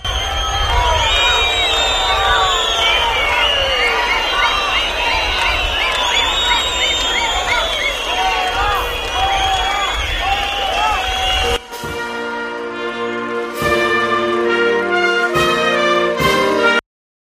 Pitidos-a-Grande-Marlaska